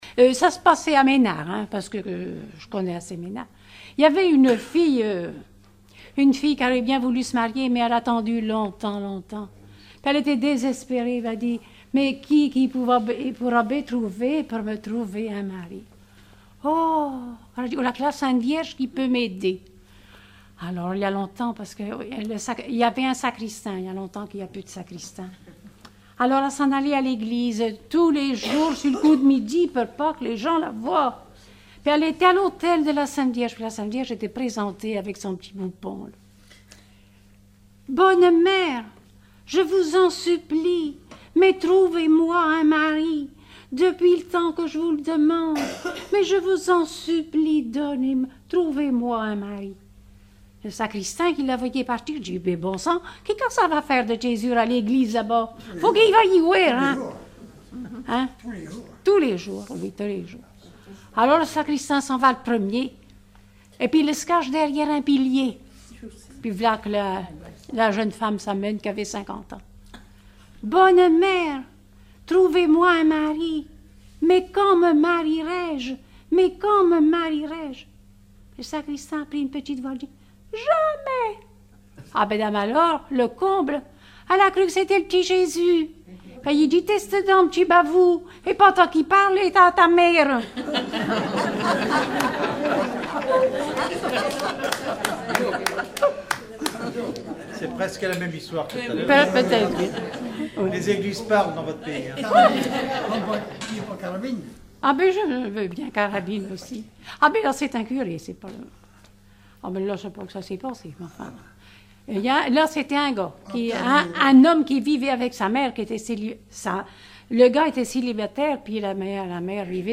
Genre sketch
Regroupement de chanteurs du canton
Catégorie Récit